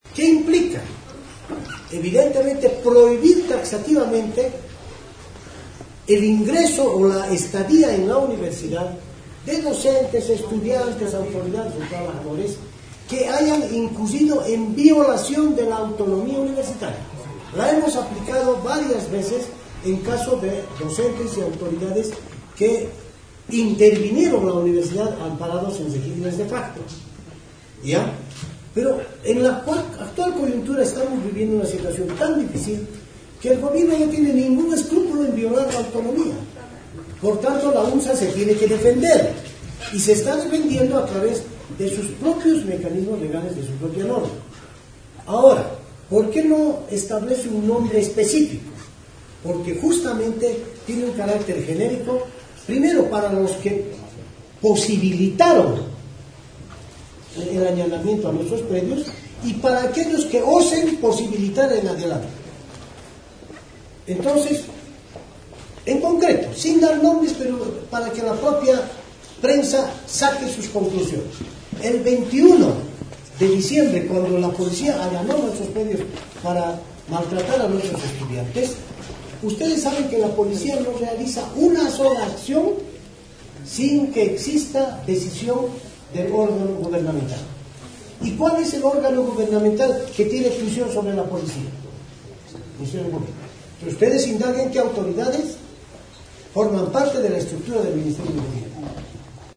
(El Alto 29 Dic.).-En conferencia de prensa, Waldo Albarracín, actual rector de la Universidad Mayor de San Andrés (UMSA), dio a conocer la determinación del Honorable Concejo Universitario, que implica vetar a autoridades involucradas en el allanamiento que sufrieron en sus instalaciones el pasado 21 de diciembre, en el conflicto médico.
Audio de Waldo Albarracín.